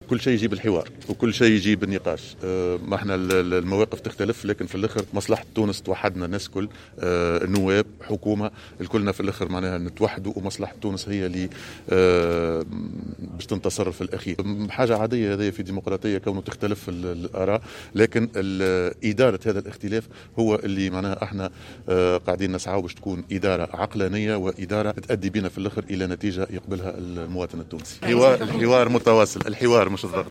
وشدّد على أهمّية إدارة الاختلاف بعقلانية للوصول الى نتيجة معتبرا أن ذلك لا يشكّل ضغطا. وجاء ذلك على هامش اشرافه على على موكب رسمي بمناسبة الاحتفال بالذكرى الرابعة والستين لتأسيس الديوانة بالمدرسة الوطنية للديوانة بفندق الجديد من معتمدية قرمبالية من ولاية نابل.